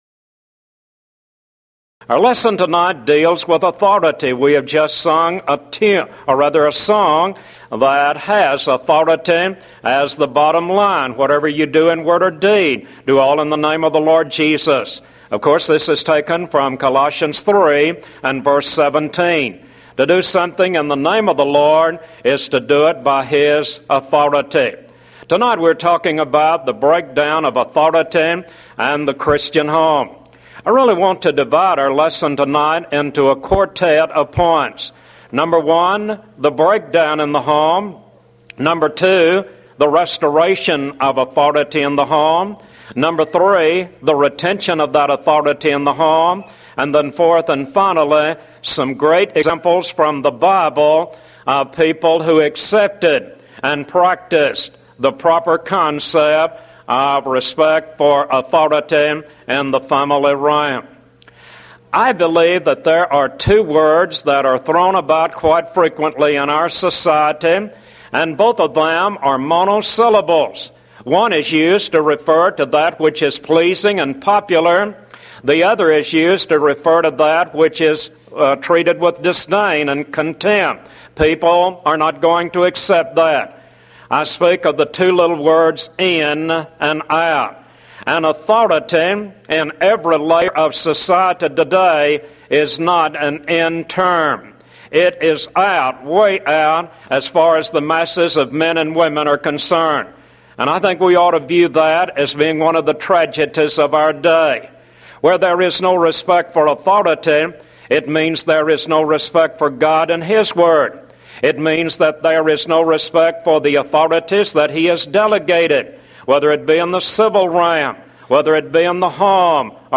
Event: 1993 Mid-West Lectures
this lecture